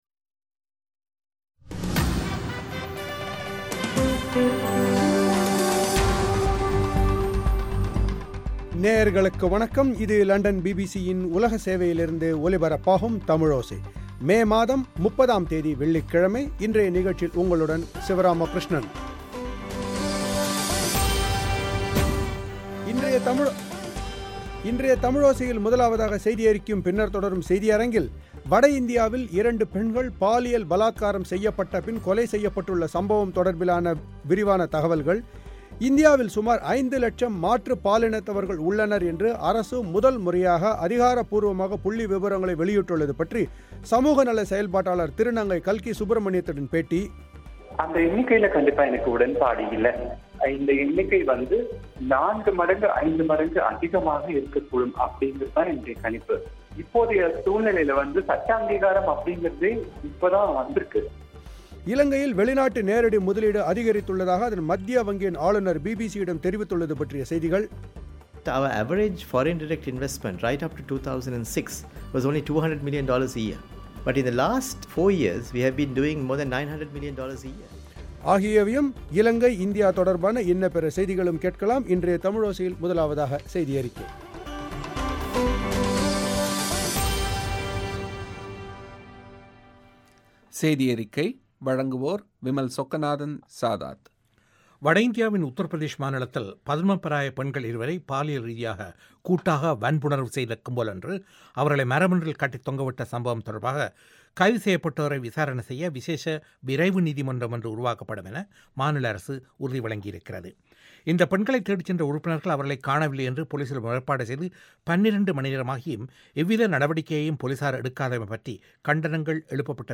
ஒரு பேட்டி